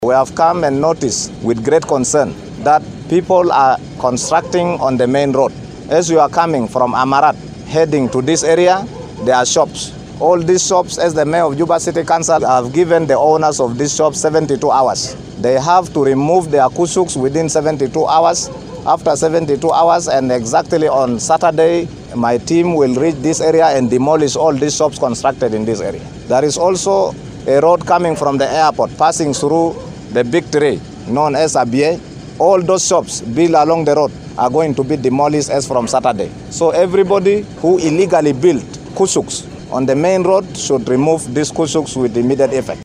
He was speaking in Thongpiny during a security assessment in the area.